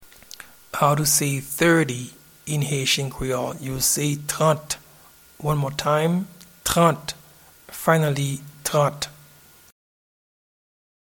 Pronunciation and Transcript:
Thirty-in-Haitian-Creole-Trant-.mp3